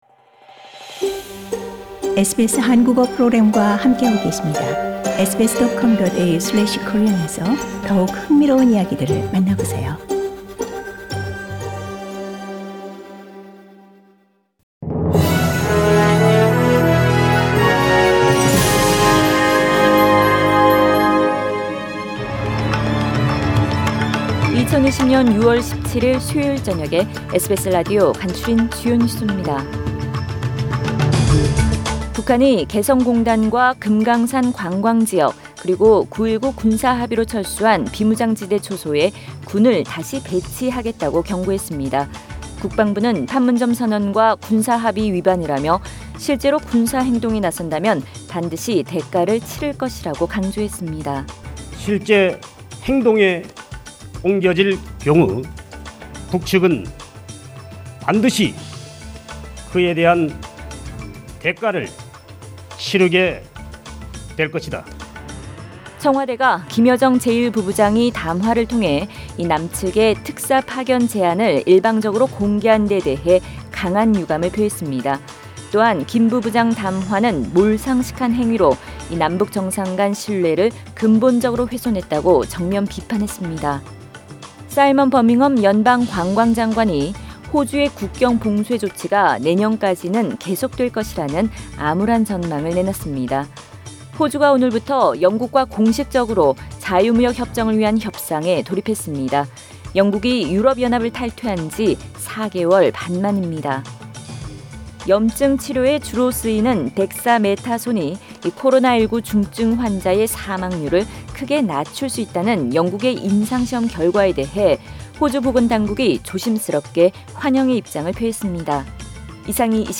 2020년 6월 17일 수요일 저녁의 SBS Radio 한국어 뉴스 간추린 주요 소식을 팟 캐스트를 통해 접하시기 바랍니다.